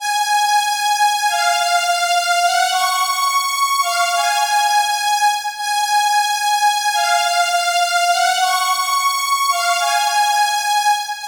弦乐 004
描述：字符串循环
Tag: 85 bpm Hip Hop Loops Strings Loops 1.90 MB wav Key : Unknown